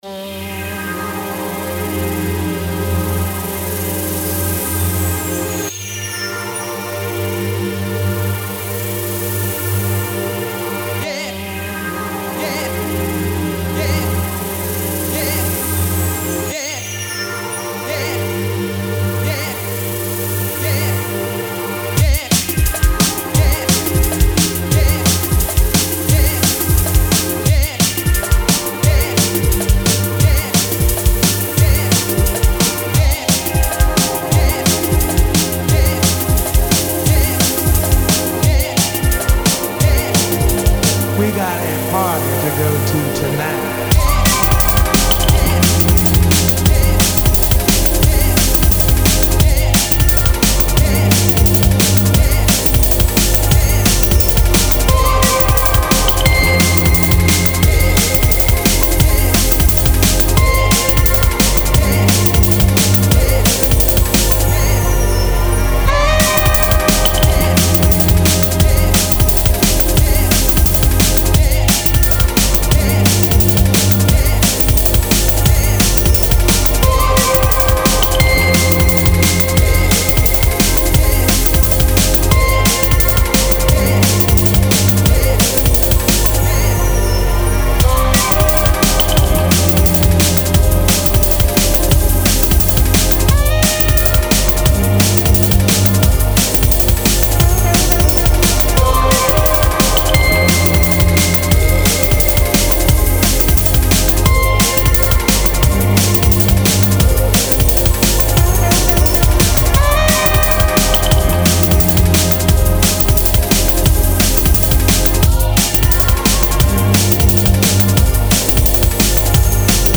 Genres Drum & Bass